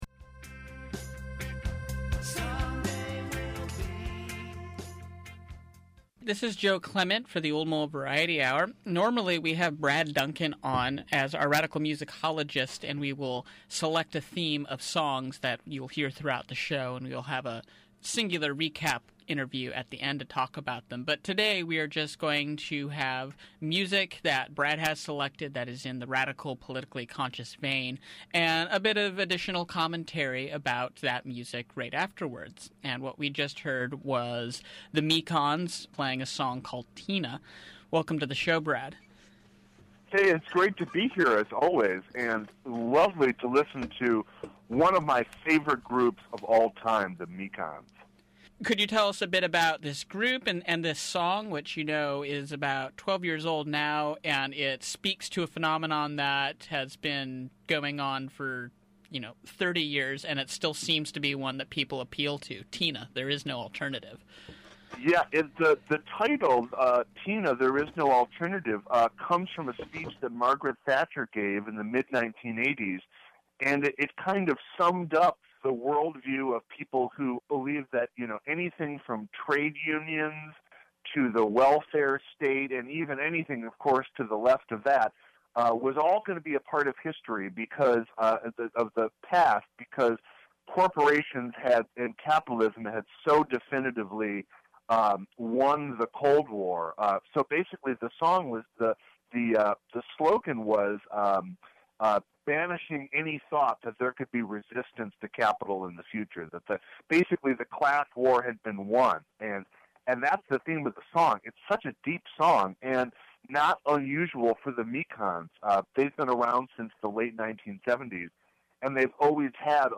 Bluegrass, Eclectic, Folk, Punk, Country